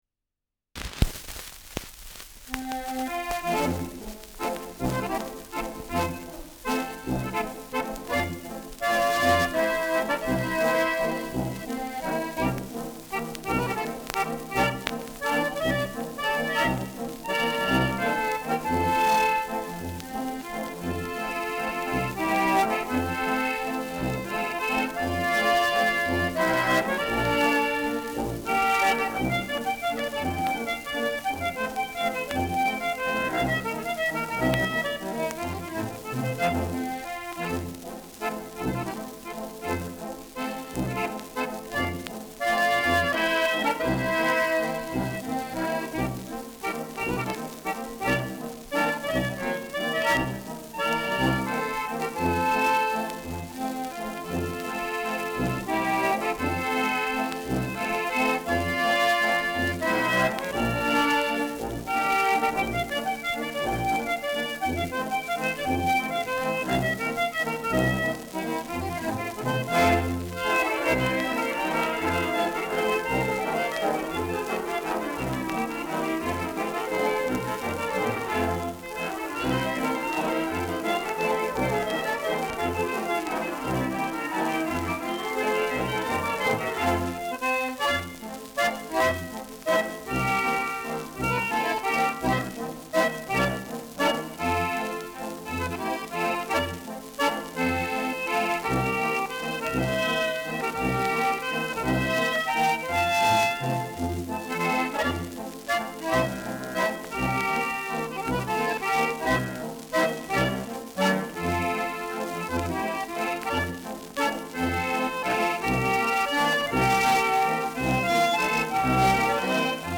Schellackplatte
Stärkeres Grundrauschen : Gelegentlich leichtes Knacken : Leichtes Leiern
Kapelle Jais (Interpretation)
[München] (Aufnahmeort)